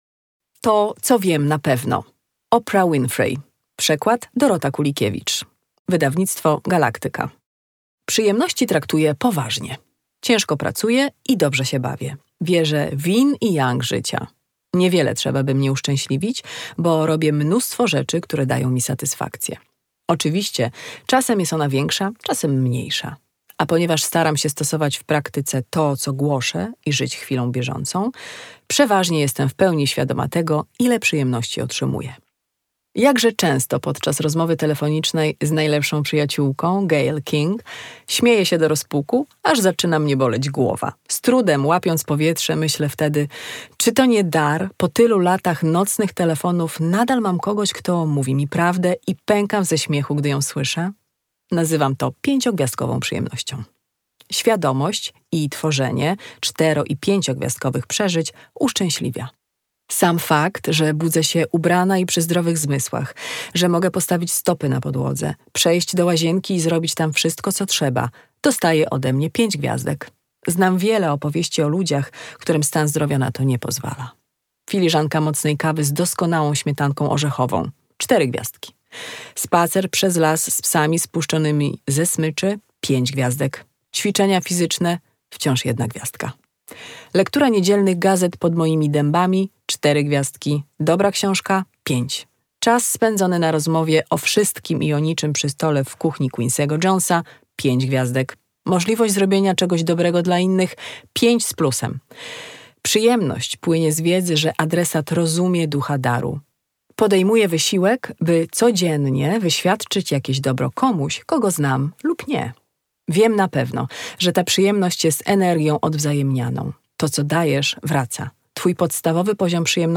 To, co wiem na pewno - Oprah Winfrey - audiobook